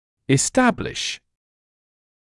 [ɪs’tæblɪʃ][ис’тэблиш]устанавливать,